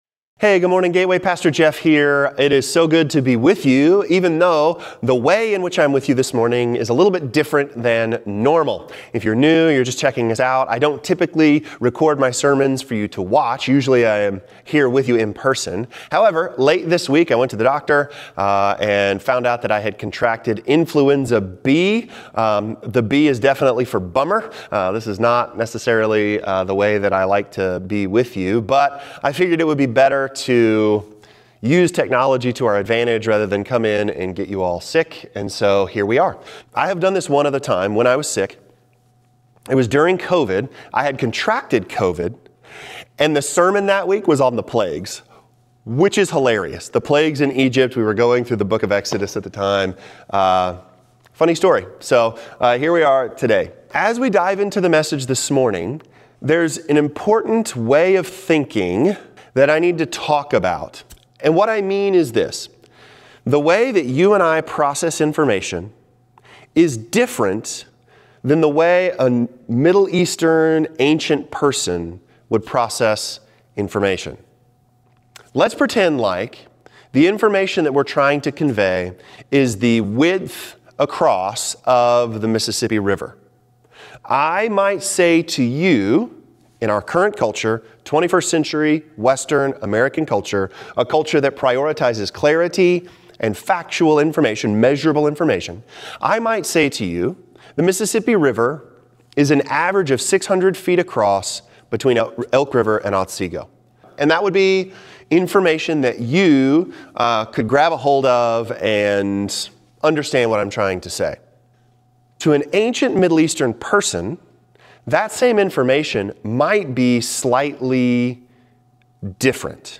The-Trial-Sermon-2.22.26.m4a